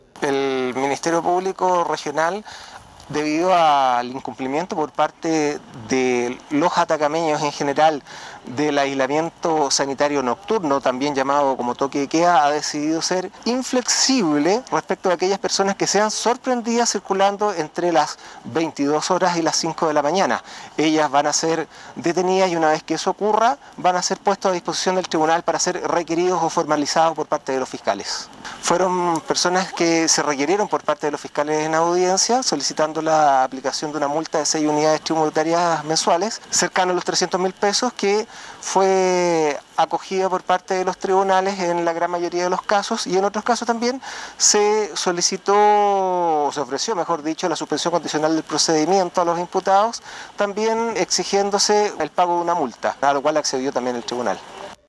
CUÑA-FISCAL-REGIONAL-DE-ATACAMA-FISCALIZACION.mp3